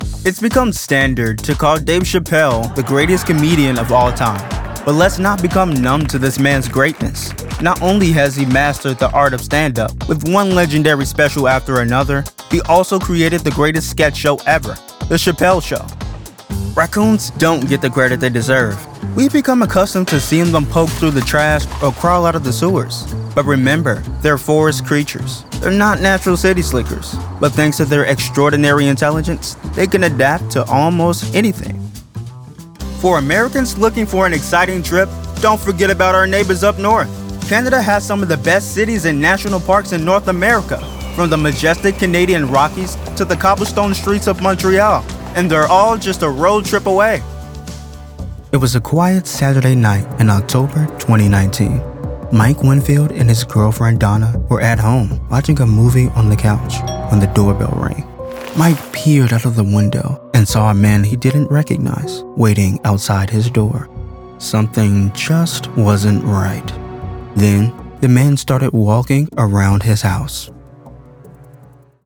Narration Demo #1
Southern English, British English, General north American English , African American/Midwest
Teen
Young Adult